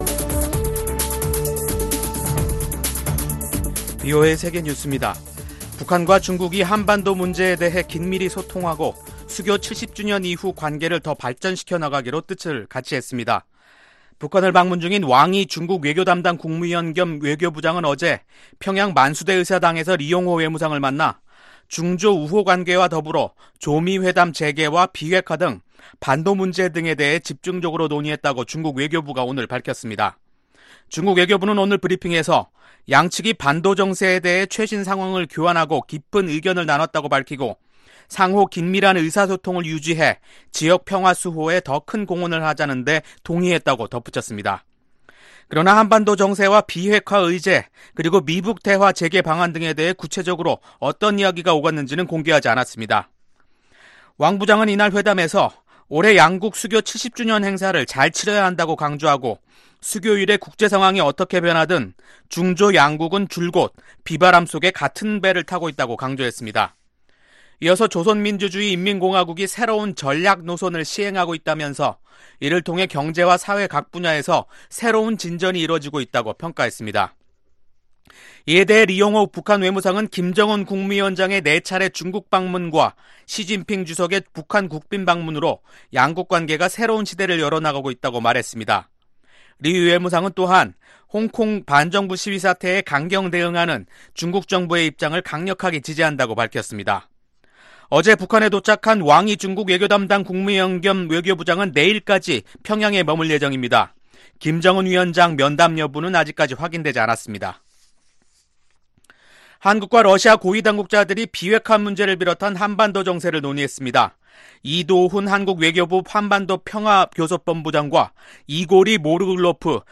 VOA 한국어 간판 뉴스 프로그램 '뉴스 투데이', 2019년 9월 3일 2부 방송입니다.